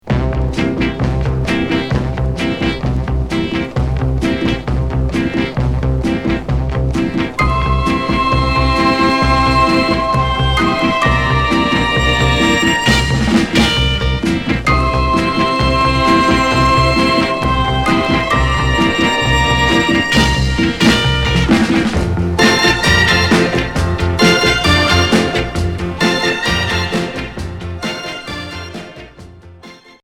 Groove easy listening